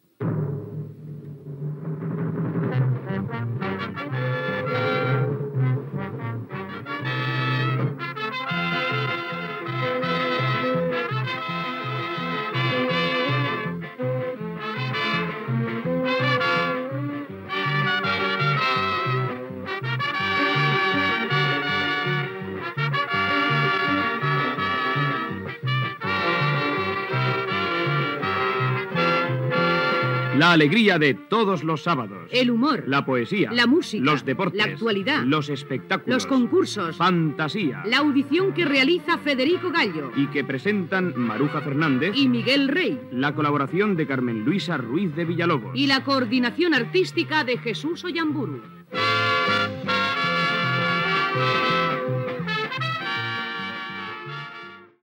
Careta del programa amb els noms de l'equip